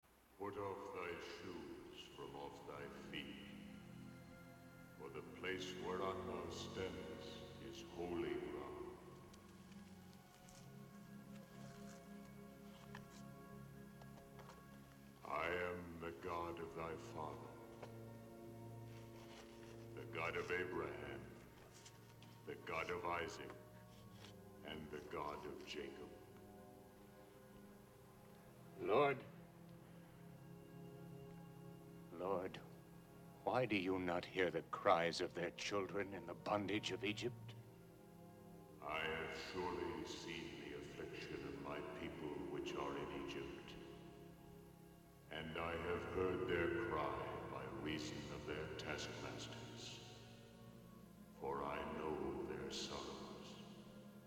The score goes down to a few violins and then fades out very quickly as Moses enters the same space as God. For the first time in the film it’s suddenly silent apart from the noises Moses is making. And when we hear the voice of God (at least this time) it’s Heston’s voice slowed down.
voice-of-god-heston.mp3